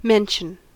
Ääntäminen
IPA : /ˈmɛnʃən/